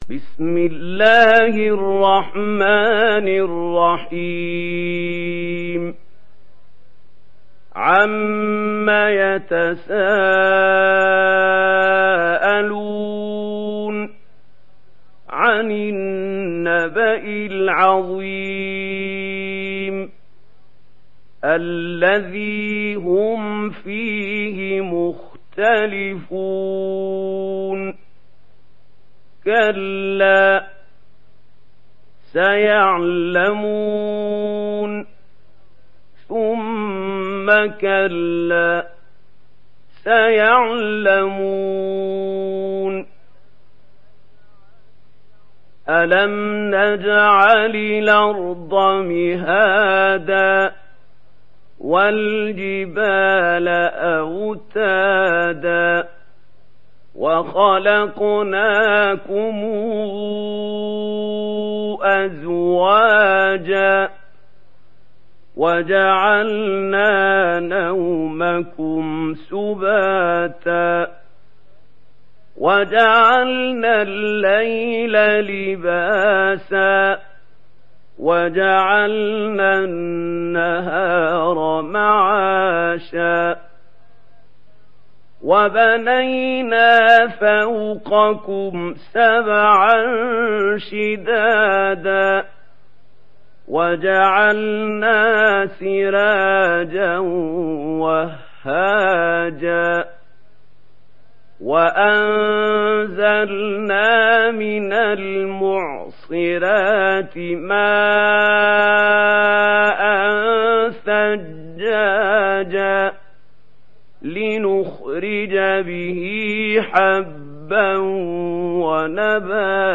Surah An-Naba MP3 in the Voice of Mahmoud Khalil Al-Hussary in Warsh Narration
Surah An-Naba MP3 by Mahmoud Khalil Al-Hussary in Warsh An Nafi narration.
Murattal